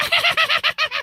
goblin.ogg